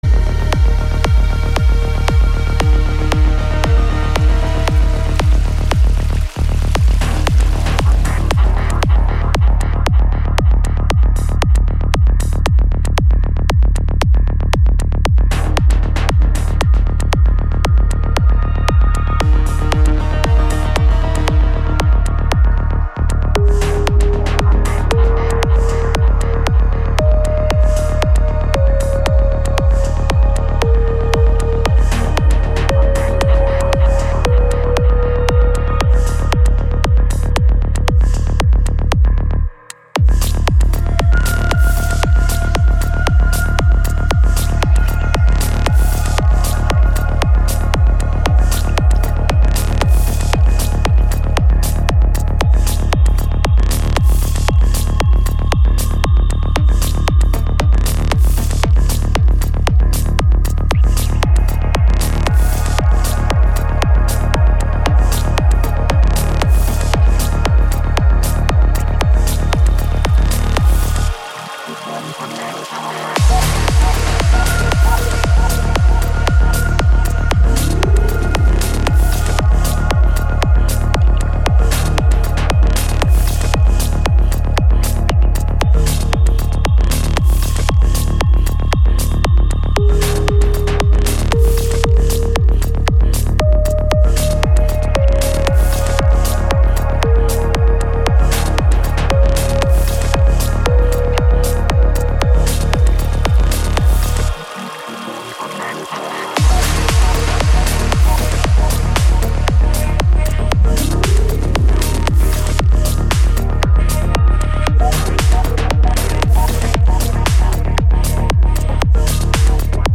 Жанр: Chill-Ambient